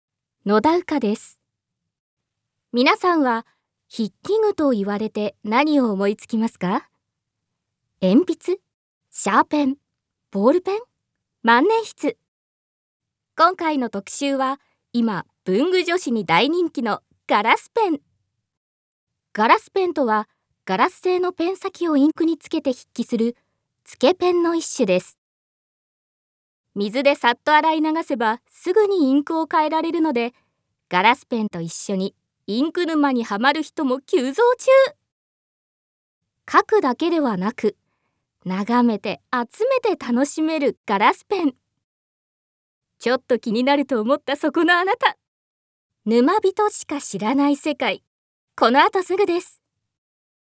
Voice Sample
ナレーション